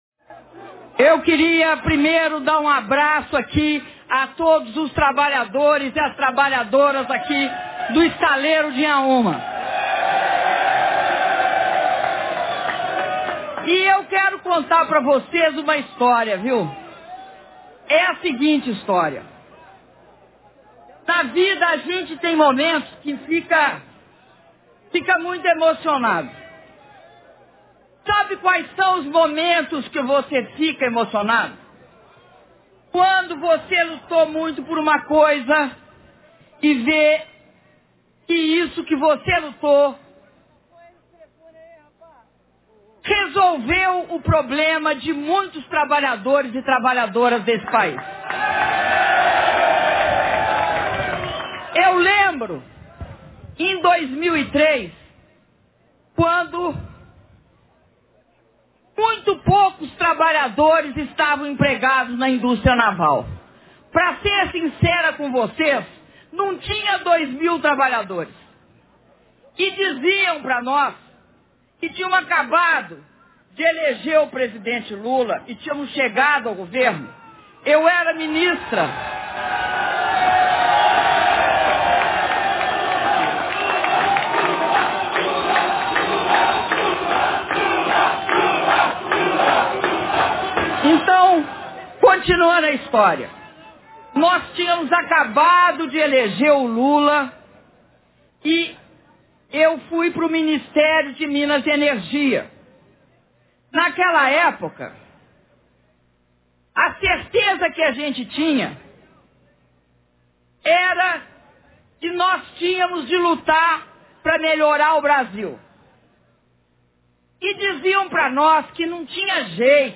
Discurso da Presidenta da República, Dilma Rousseff, durante visita às obras da P-74 no Estaleiro Inhaúma
Rio de Janeiro-RJ, 11 de setembro de 2013